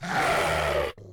growl.ogg